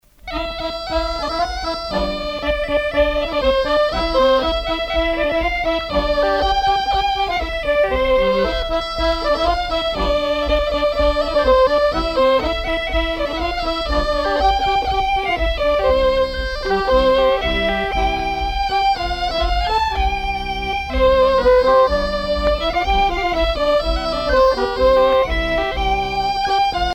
Bourrée à deux temps
Pièce musicale éditée